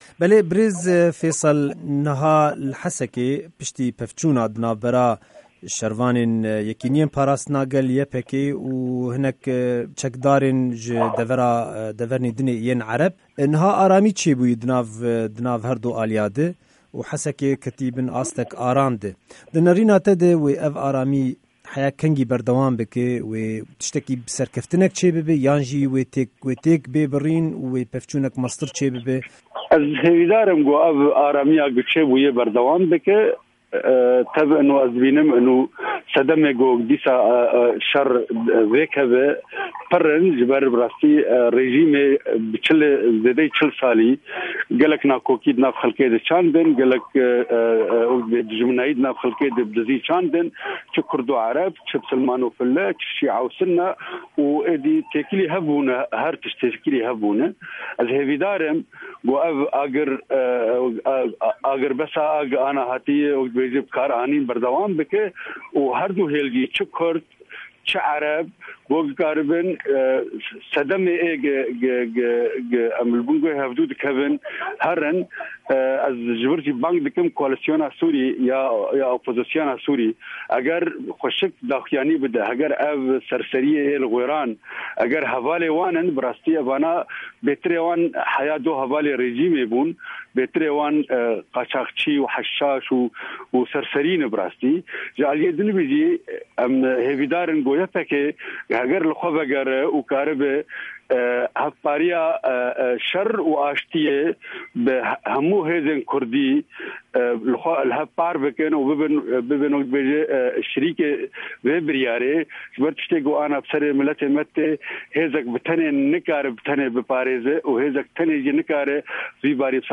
Hevpeyvina